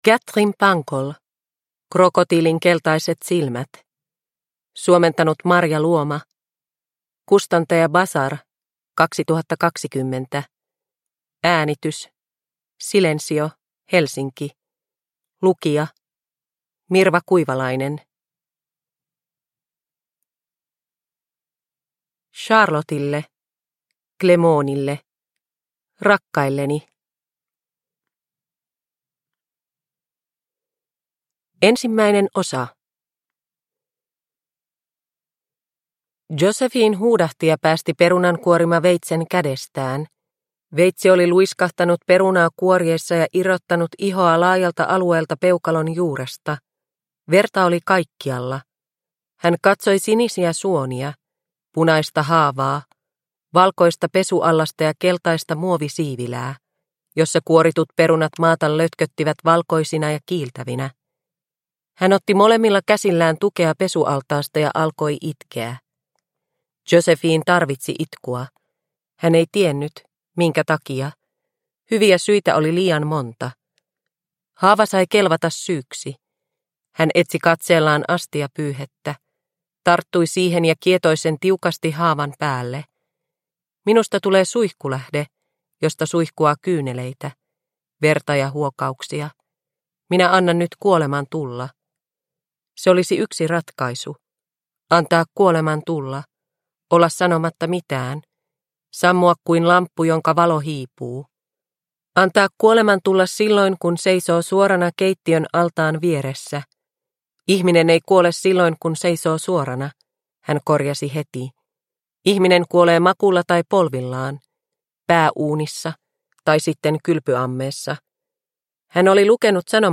Krokotiilin keltaiset silmät – Ljudbok – Laddas ner